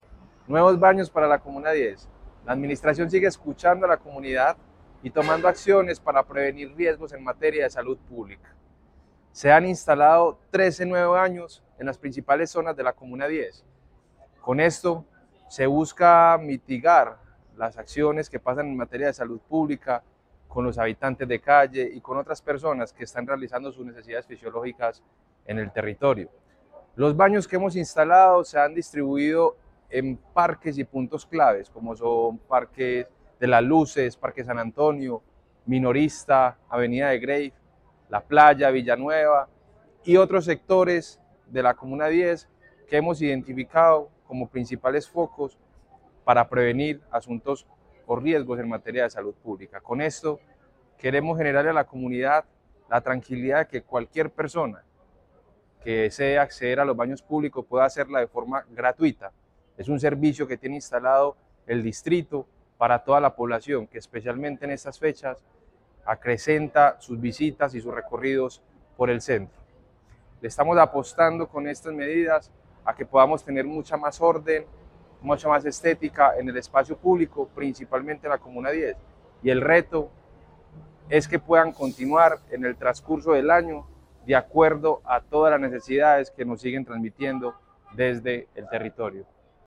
Palabras de David Ramírez, subsecretario de Espacio Público Con el compromiso de fortalecer la salud pública, la Alcaldía de Medellín instaló 13 nuevos baños en el Centro.